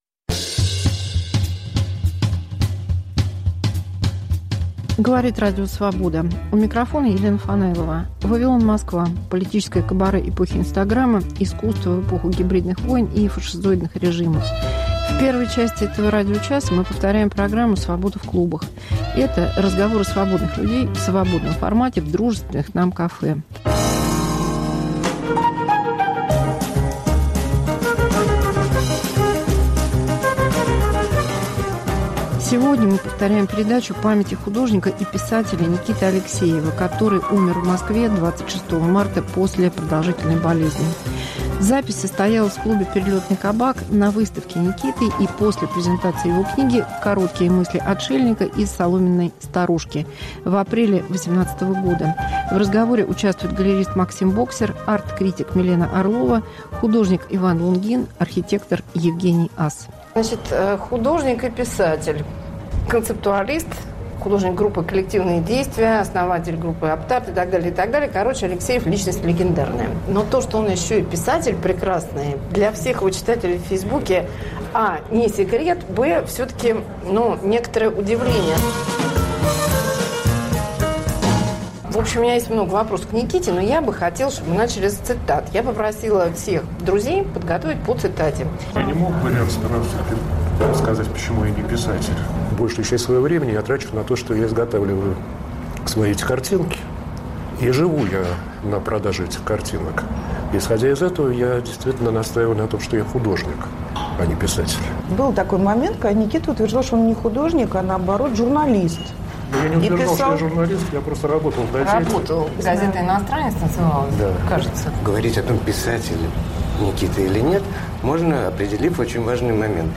Мегаполис Москва как Радио Вавилон: современный звук, неожиданные сюжеты, разные голоса. 1. Памяти художника и писателя Никиты Алексеева. 2.